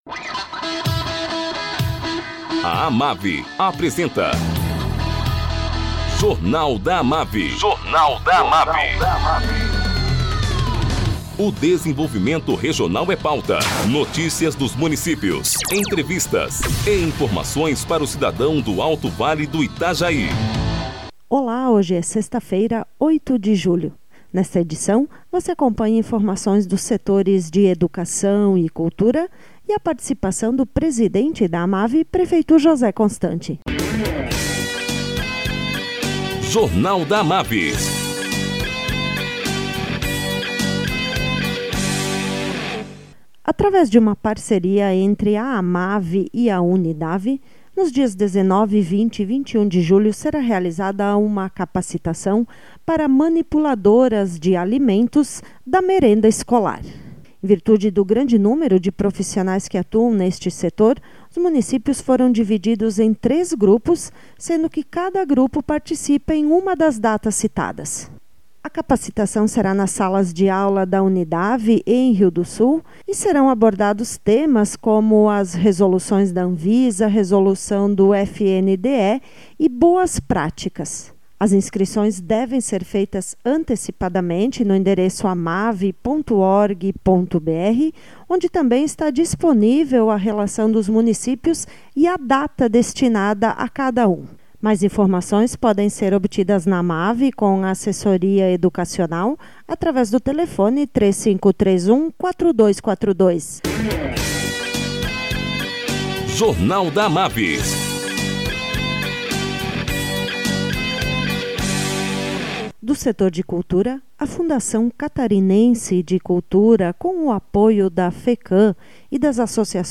Presidente da AMAVI, prefeito José constante, fala sobre o lançamento do edital de chamamento público para Procedimento de Manifestação de Interesse- PMI, visando a realização de estudos de viabilidade para o projeto de implantação do Parque de Processamento e Tratamento dos Resíduos Sólidos.